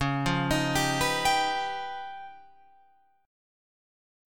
C#9b5 chord